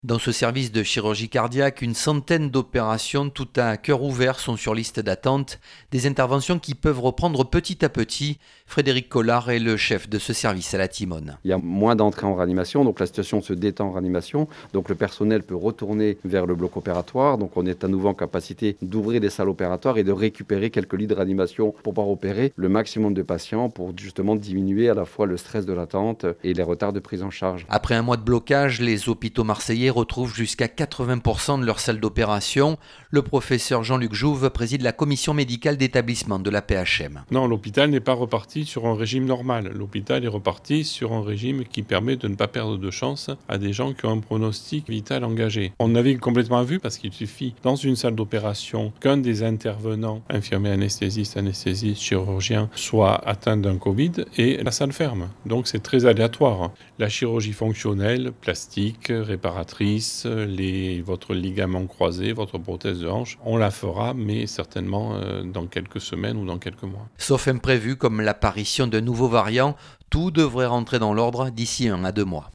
À l'hôpital de la Timone, les salles opératoires réouvrent